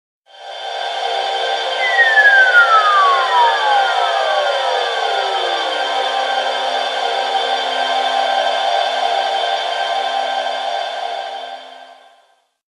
nokia-portamento_24984.mp3